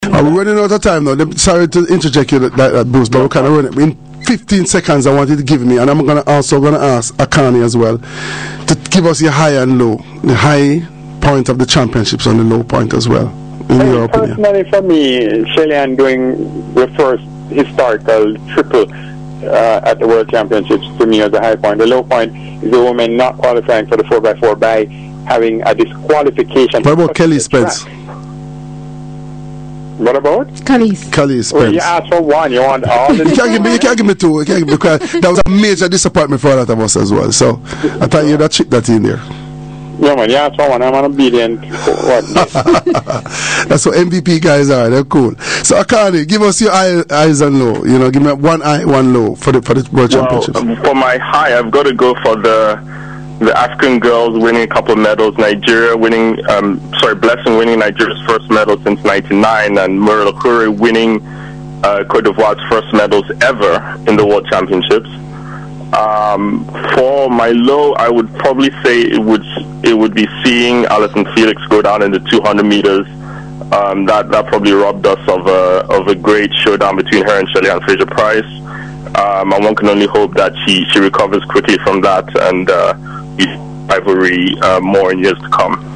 Here are the final snippets from my radio interview on Sportsline on Hot102FM in Jamaica last week Monday.